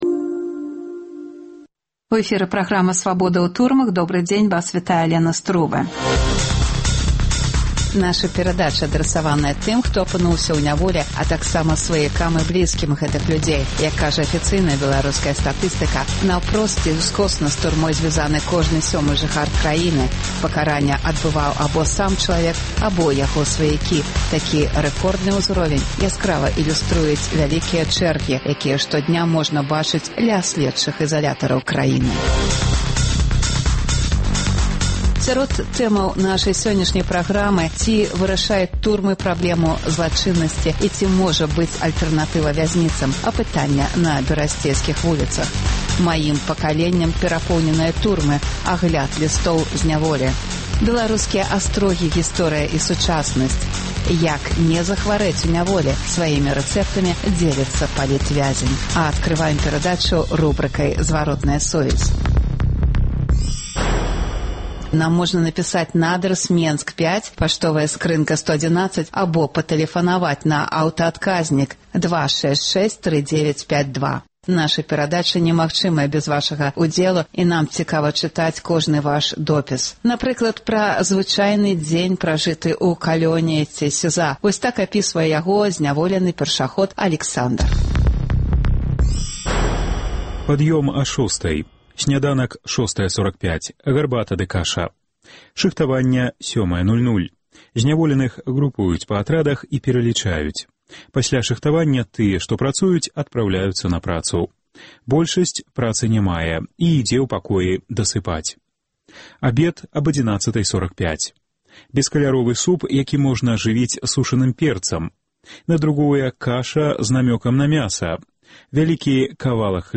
Ці вырашаюць турмы праблему злачыннасьці і ці можа быць альтэрнатыва вязьніцам? Апытаньне на берасьцейскіх вуліцах. Гісторыя і сёньняшні дзень беларускіх пэнітэнцыярных устаноў.